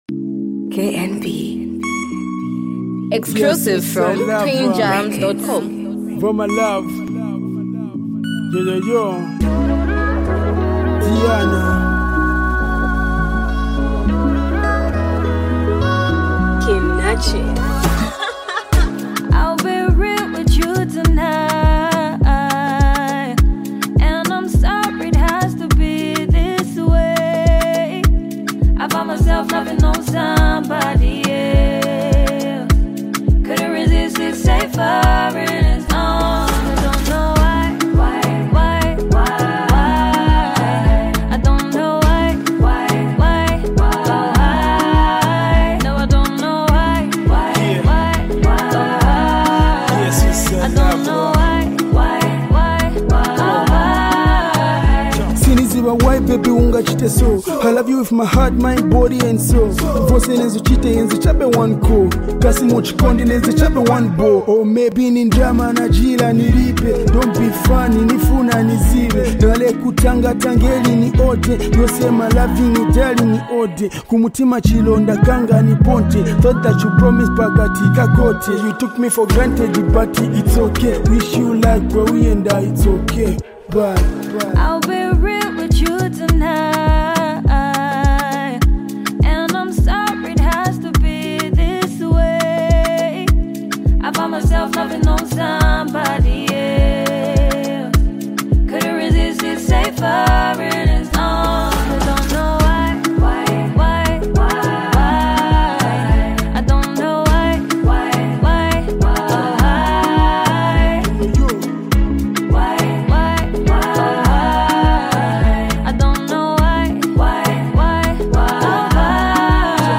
female singer